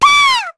Lavril-Vox_Attack4_kr.wav